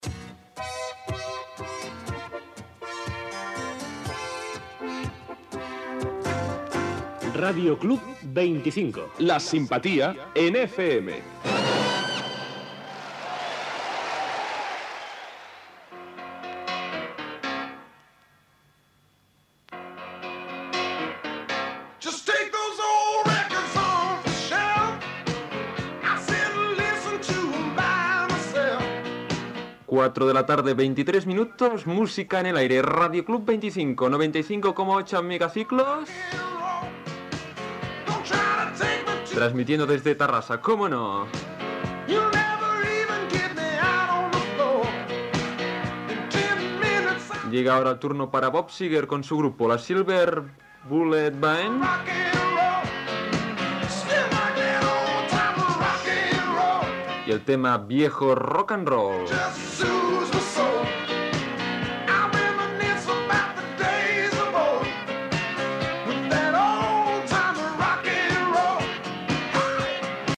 Indicatiu de l'emissora, hora, identificació de l'emissora i del programa i tema musical.
Musical
FM